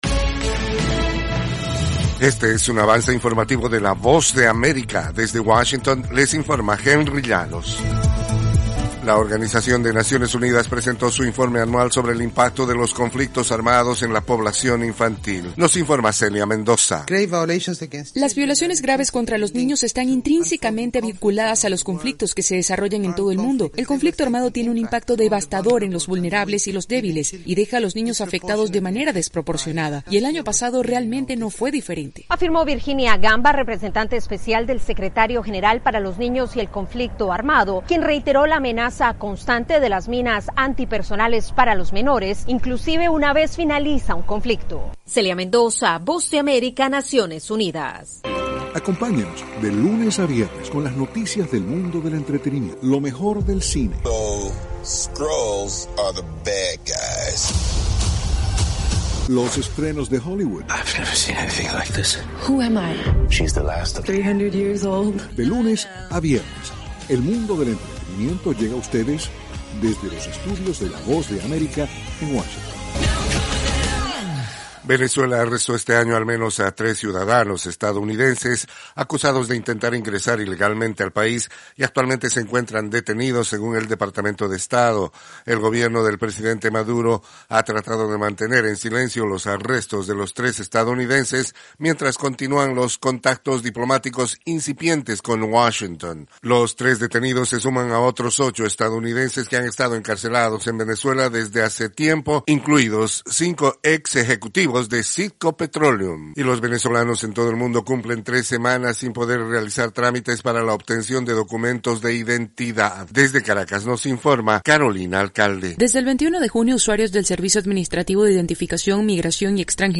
Cápsula informativa de tres minutos con el acontecer noticioso de Estados Unidos y el mundo.
Desde los estudios de la Voz de América en Washington